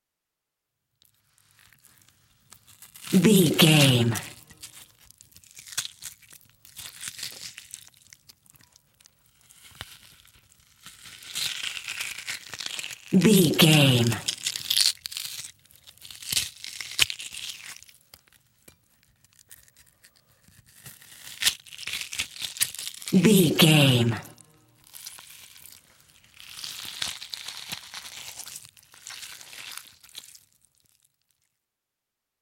Creature eating rip tear flesh
Sound Effects
scary
disturbing
horror